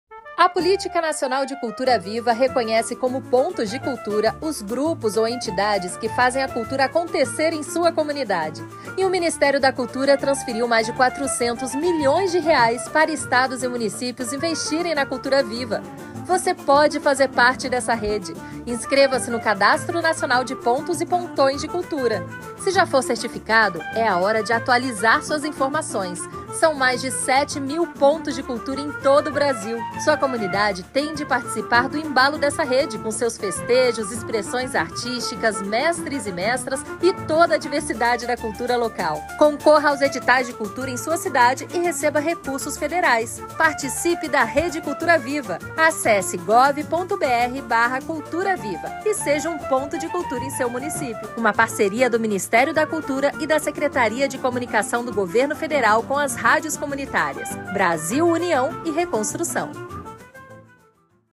Ministerio-da-Cultura-Spot_PNCV.mp3